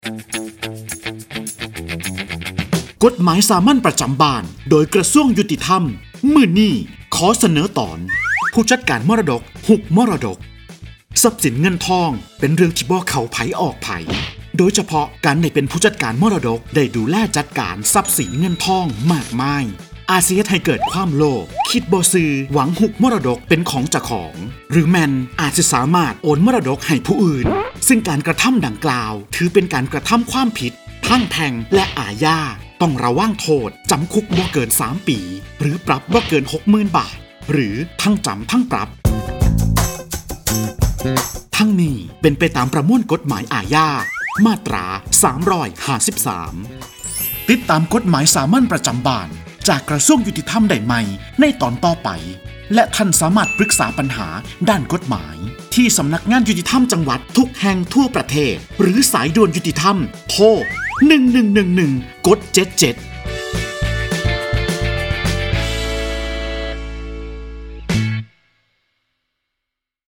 กฎหมายสามัญประจำบ้าน ฉบับภาษาท้องถิ่น ภาคอีสาน ตอนผู้จัดการมรดก ฮุบมรดก
ลักษณะของสื่อ :   คลิปเสียง, บรรยาย